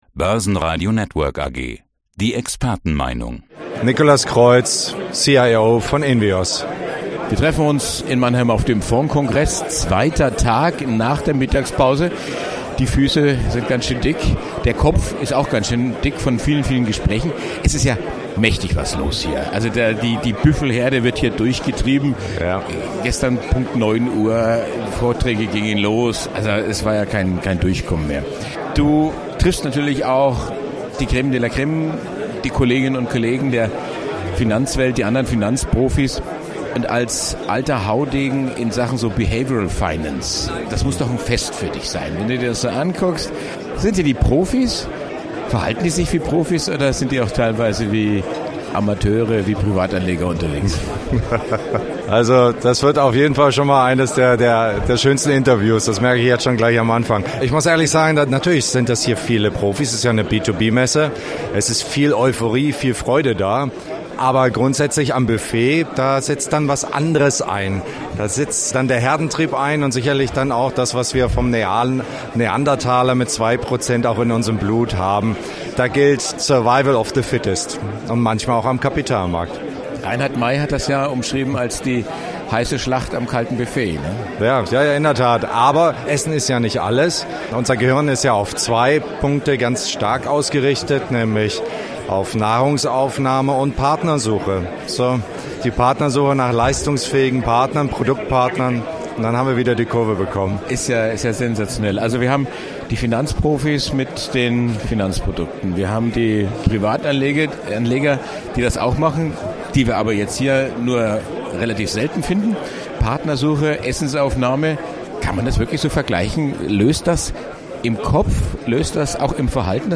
Zum Börsenradio-Interview